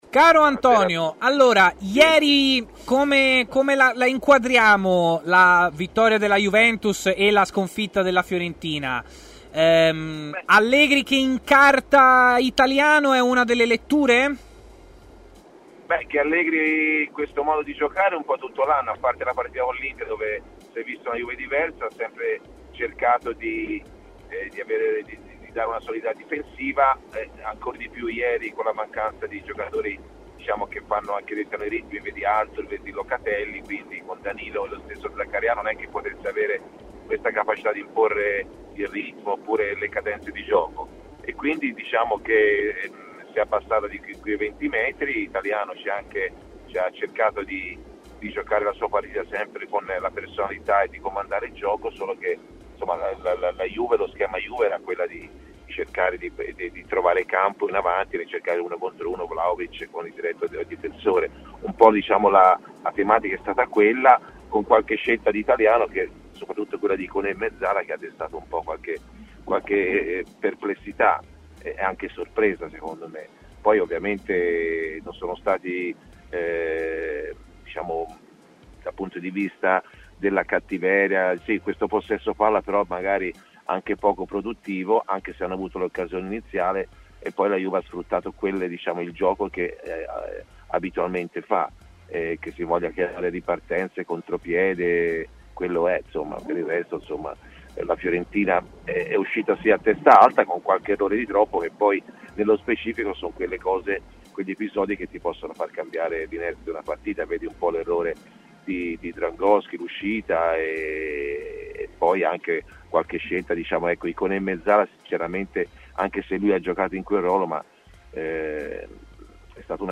L'ex centrocampista Antonio Di Gennaro, opinionista di TMW Radio, è intervenuto in diretta durante Stadio Aperto e ha detto la sua sull'eliminazione della Fiorentina dalla Coppa Italia per opera della Juventus.